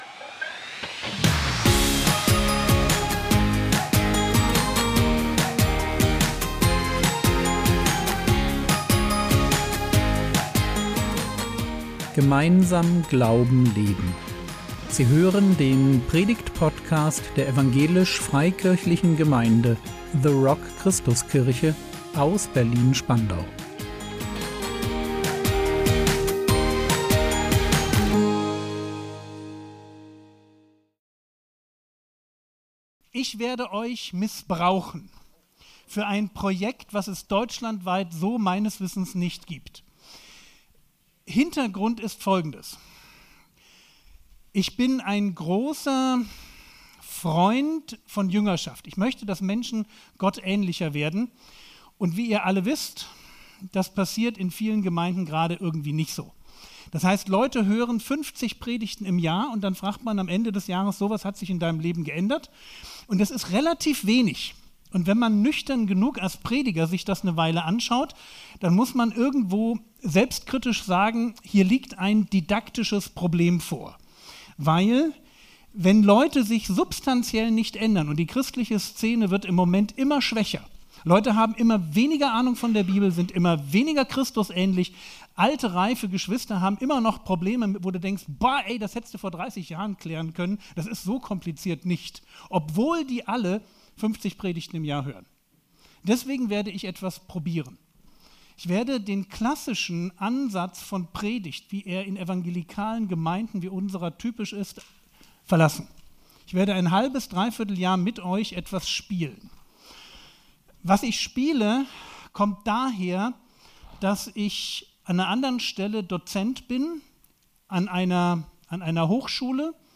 Zweifel | 09.11.2025 ~ Predigt Podcast der EFG The Rock Christuskirche Berlin Podcast